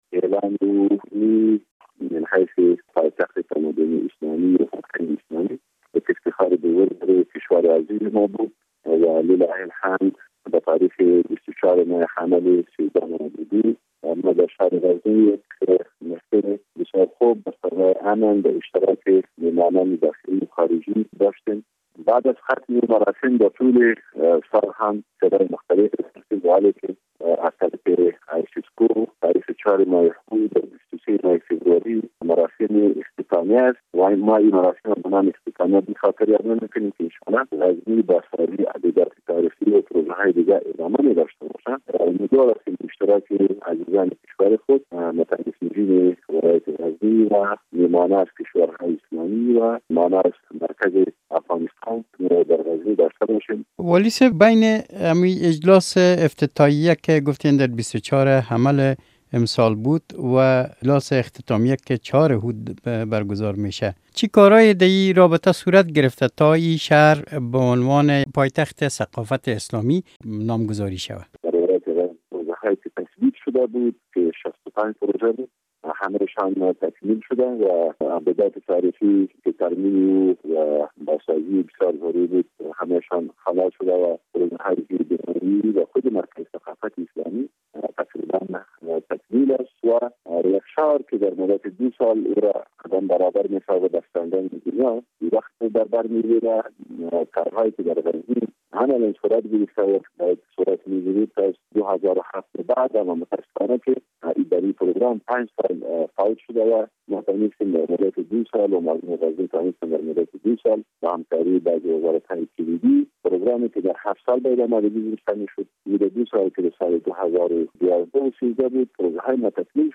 مصاحبه با والی غزنی در مورد نامگذاری آن ولایت به حیث مرکز ثقافت اسلامی